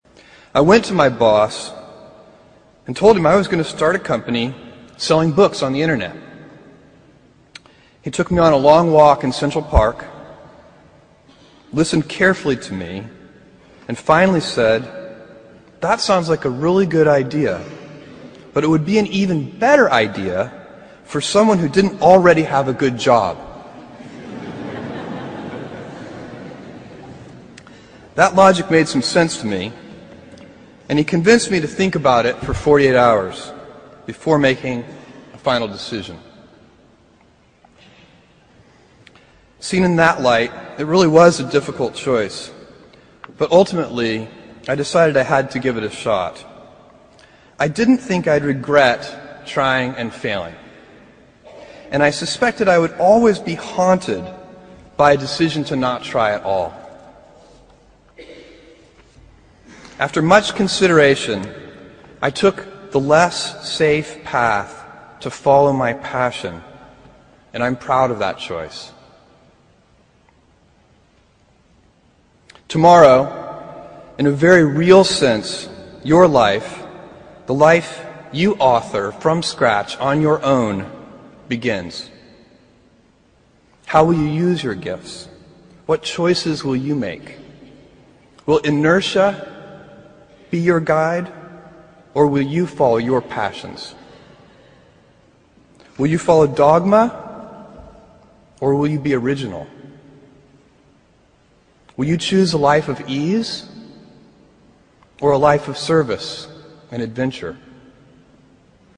公众人物毕业演讲 第305期:亚马逊创始人贝佐斯普林斯顿大学毕业演讲(4) 听力文件下载—在线英语听力室